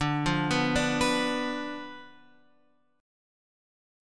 D Minor 6th